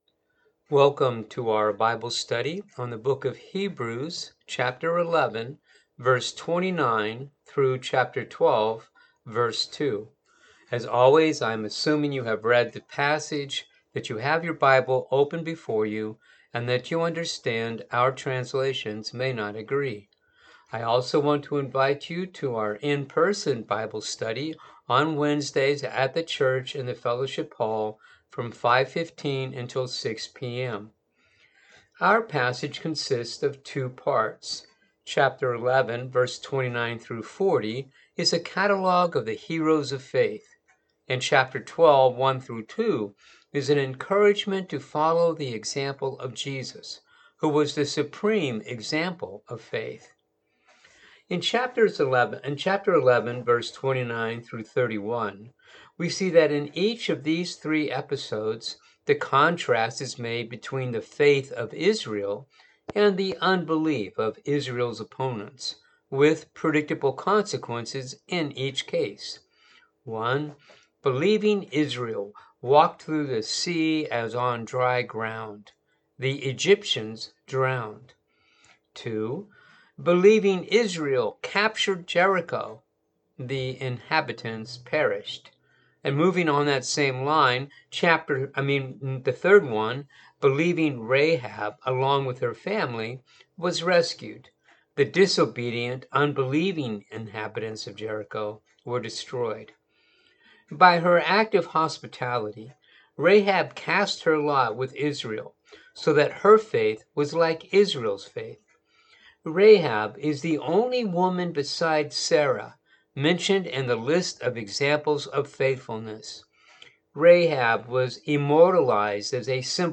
Bible Study for the August 14 Service